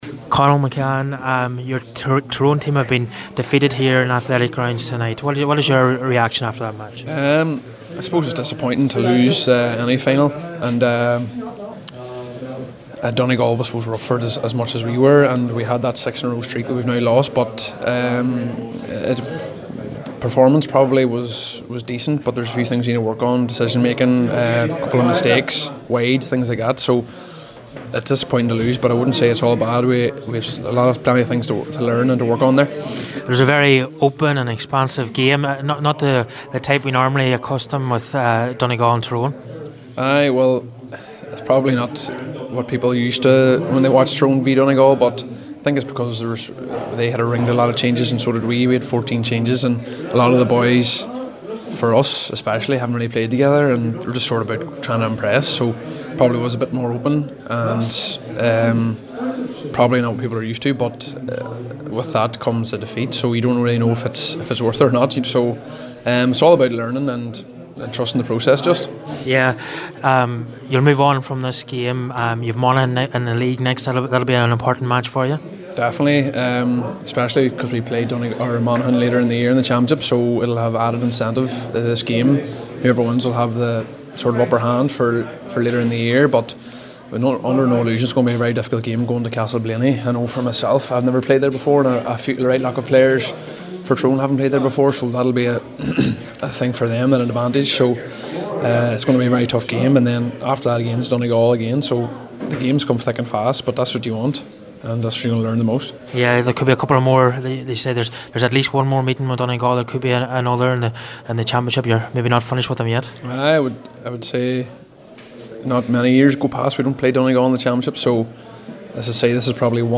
his thoughts after the game…